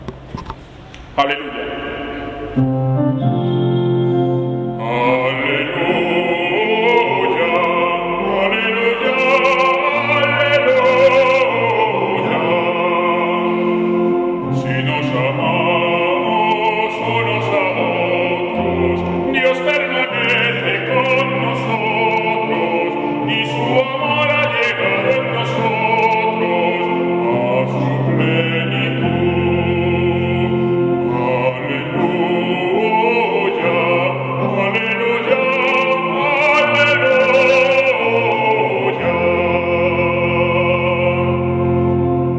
aleluya27.mp3